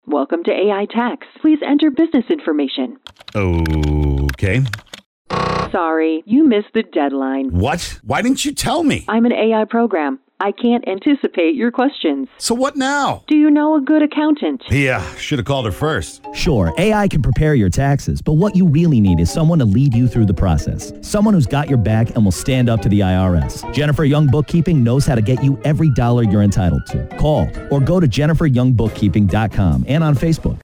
Commercials